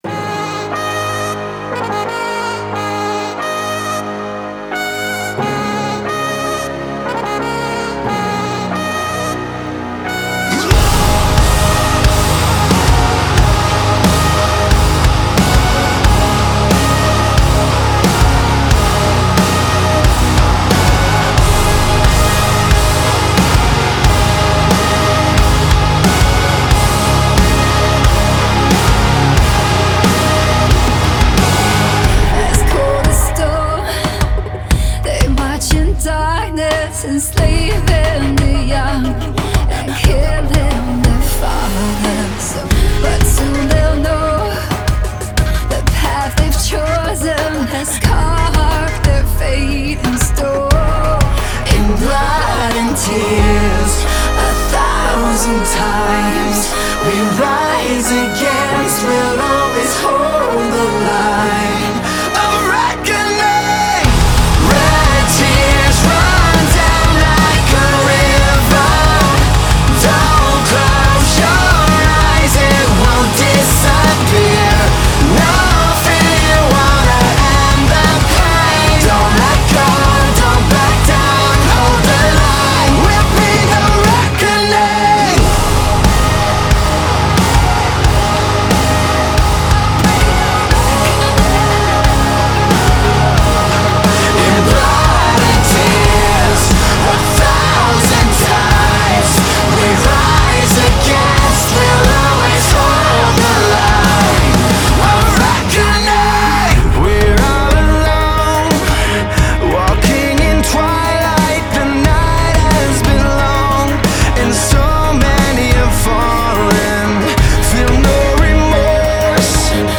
MetalMetal Symphonique